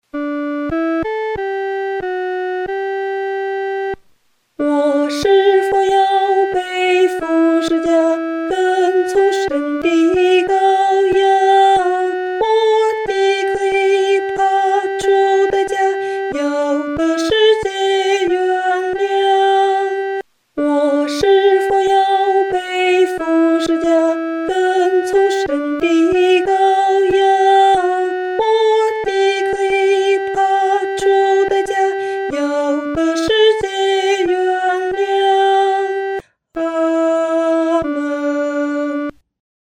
女低